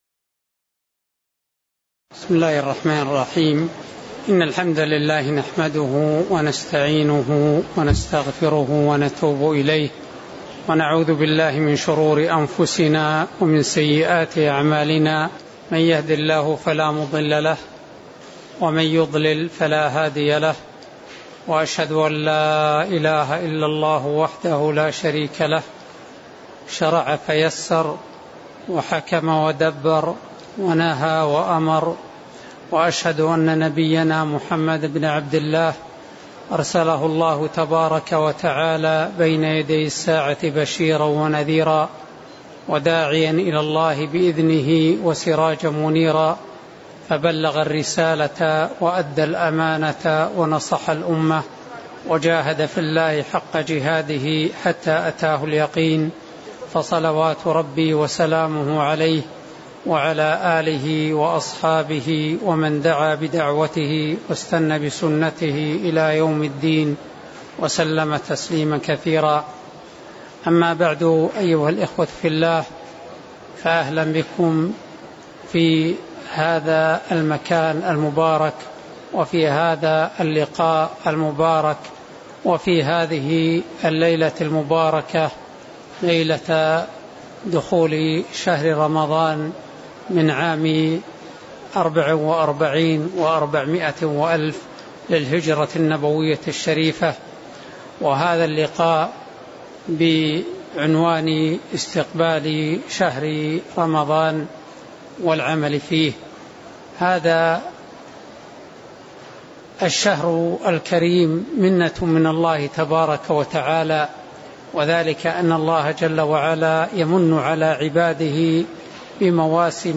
تاريخ النشر ٣٠ شعبان ١٤٤٤ هـ المكان: المسجد النبوي الشيخ